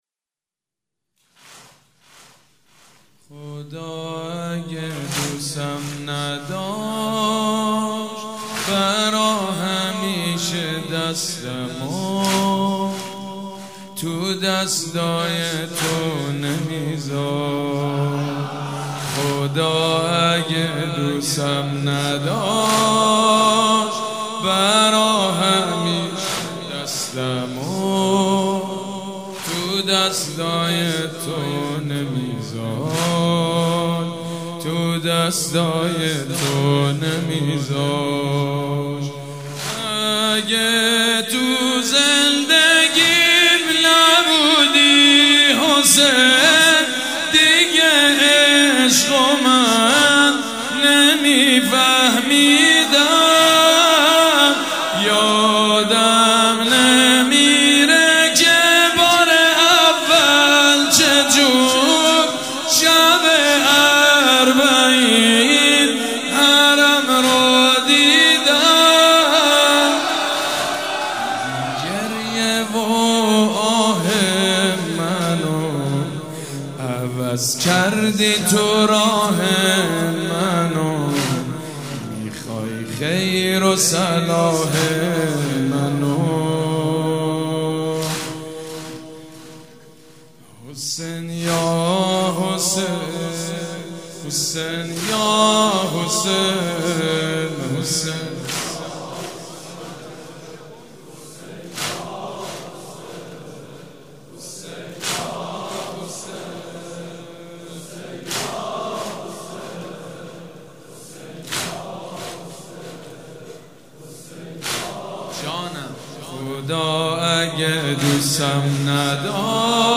شب اول محرم الحرام
مداح
حاج سید مجید بنی فاطمه
مراسم عزاداری شب اول